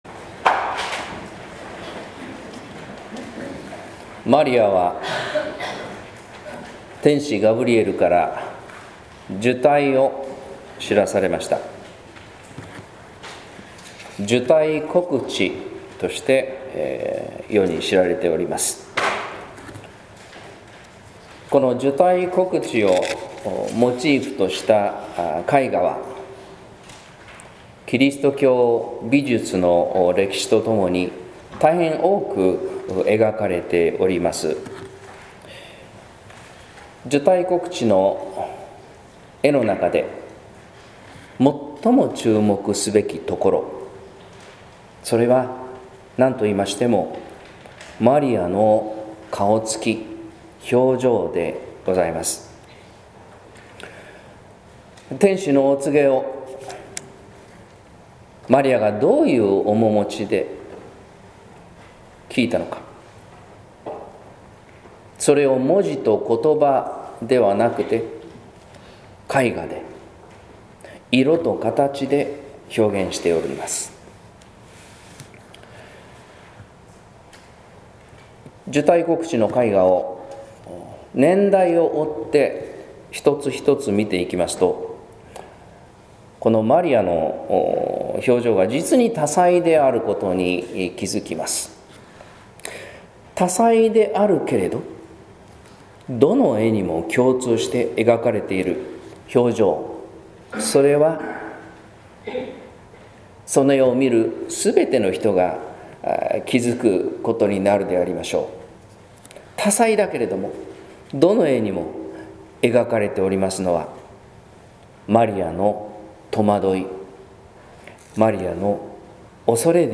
説教「マリアが歌う喜びの歌」（音声版） | 日本福音ルーテル市ヶ谷教会